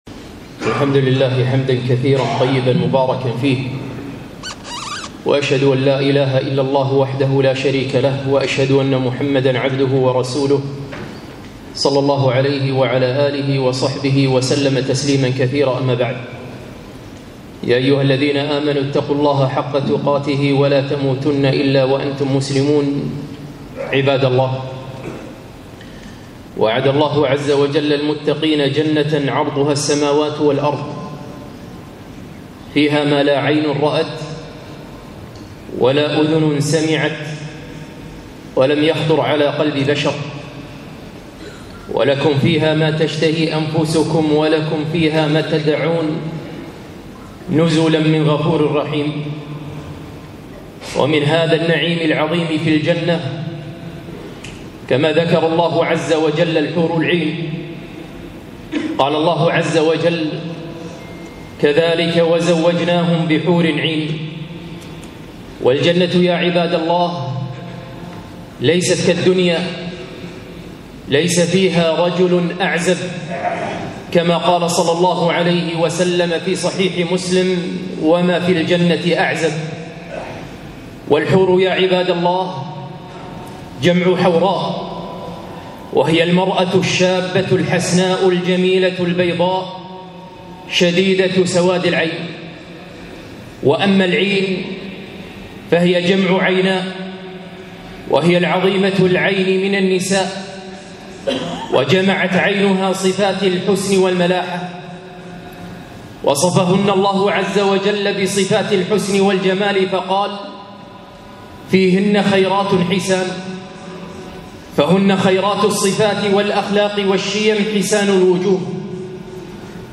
خطبة - صفات الحور العين